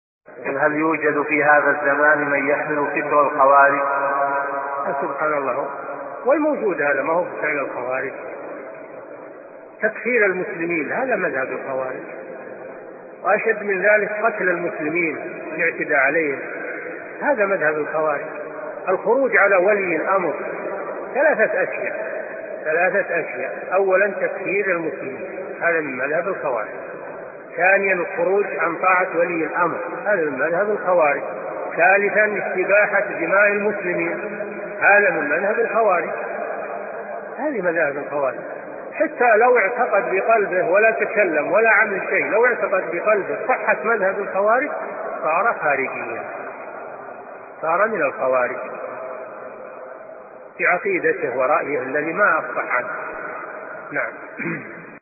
Aus einer Audioaufnahme des Scheichs: